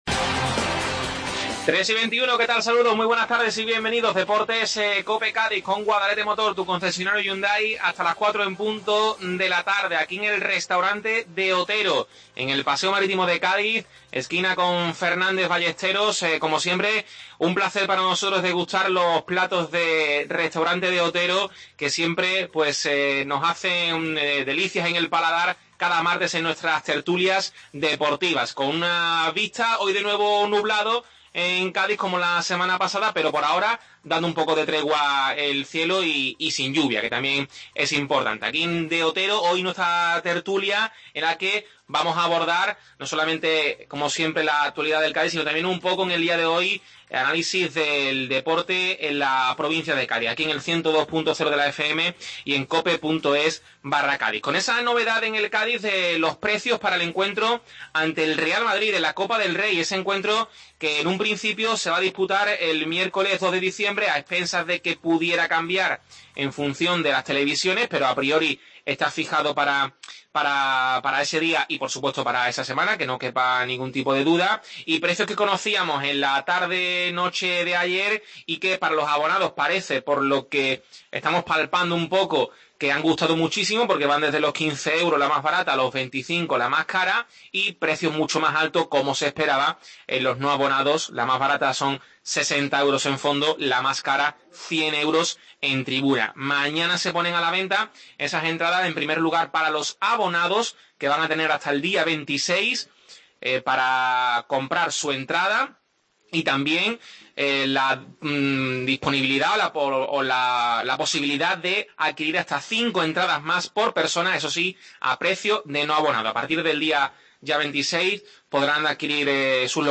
AUDIO: Tertulia en el Restaurante De Otero. Hoy como invitado especial el diputado de deportes de la Diputación de Cádiz Jaime Armario....